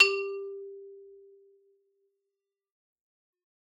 Xylo_Medium_G3_ff_01_far.wav